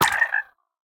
25w18a / assets / minecraft / sounds / mob / frog / death1.ogg
death1.ogg